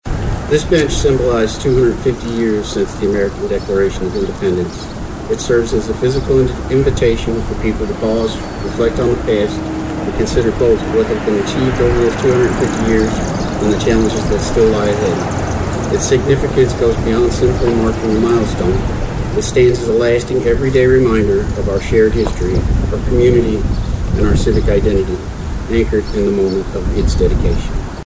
The ceremony was held on the front lawn of the Caldwell County Courthouse with a good attendance of residents.